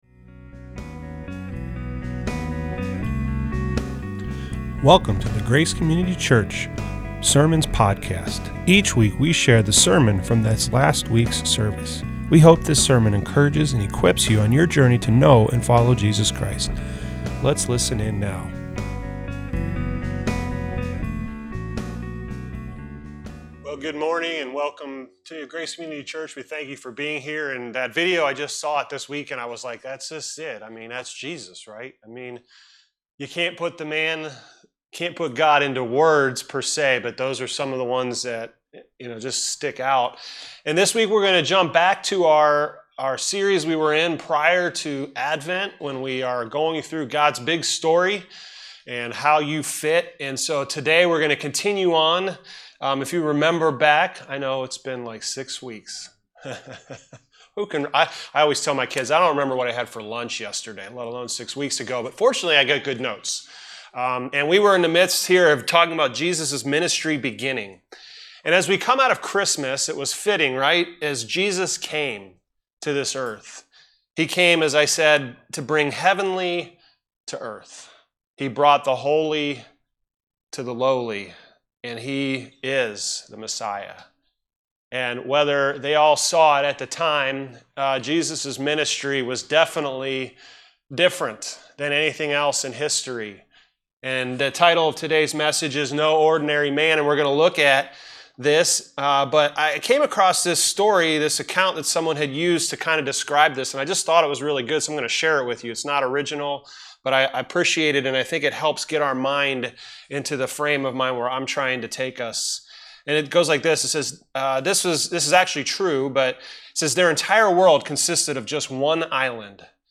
This week we returned to our series titled 'God's big story, and how YOU fit!'. The title of this week's message is 'No Ordinary man'.